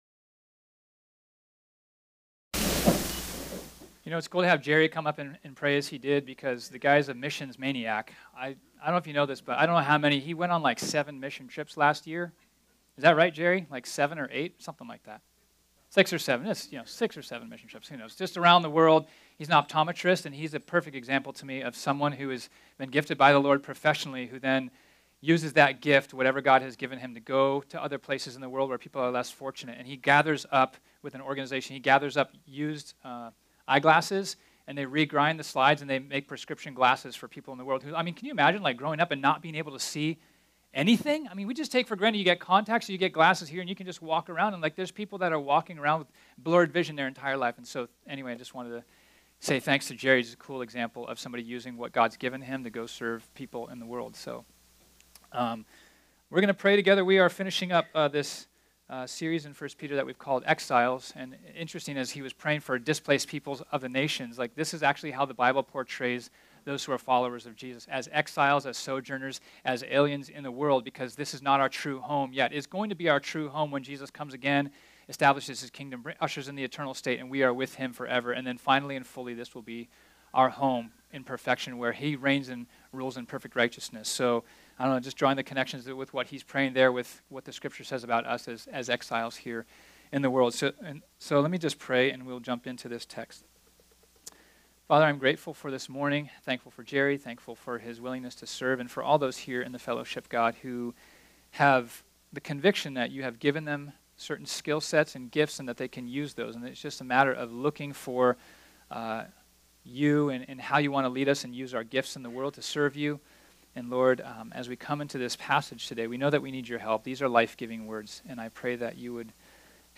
This sermon was originally preached on Sunday, April 29, 2018.